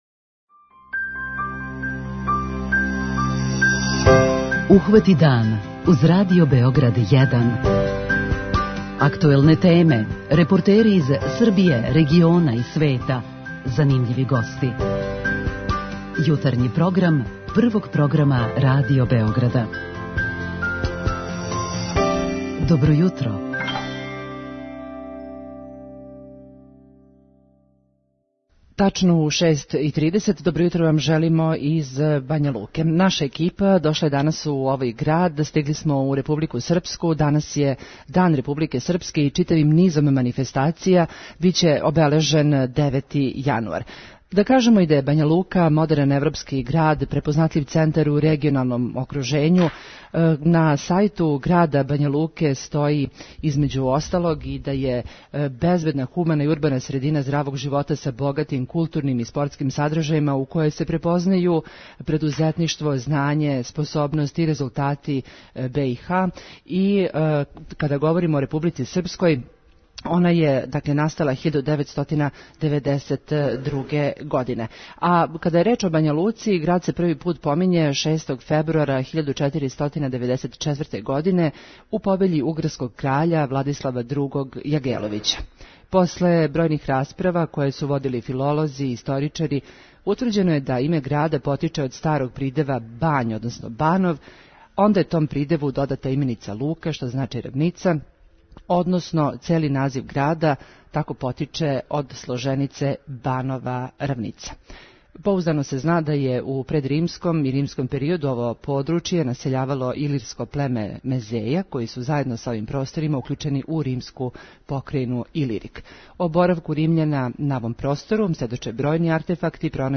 Уживо из Бањалуке!